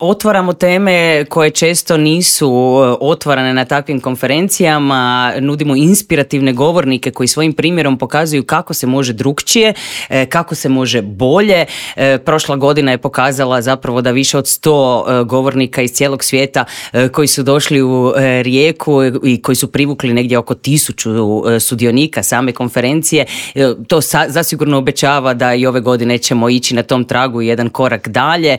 Intervjuu